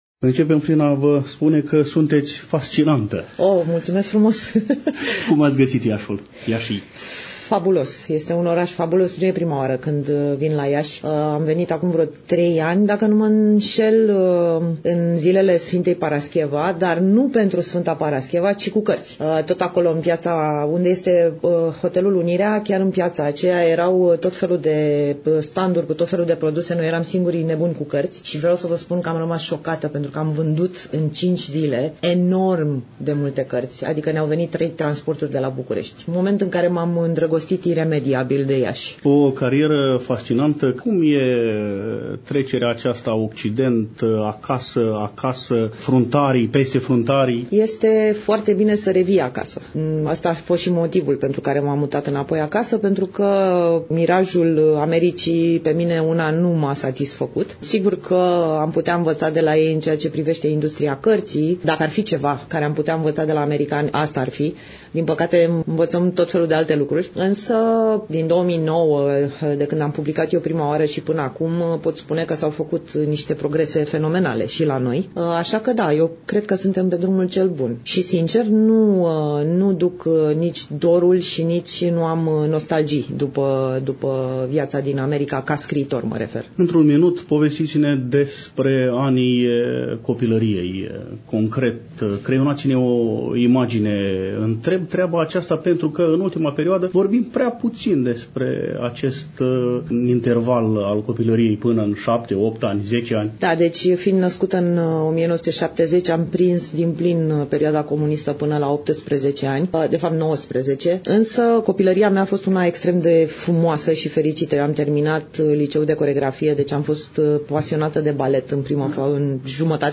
Stimați prieteni, nu demult, la Iași, în incinta Filialei „Ion Creangă” (Casa de Cultură a Sindicatelor) a Bibliotecii Județene „Gh. Asachi” Iași, s-a desfășurat a interesantă întâlnire cu publicul a scriitorilor de Fantasy, eveniment cultural din programul Festivalului Internațional de Literatură și Traducere.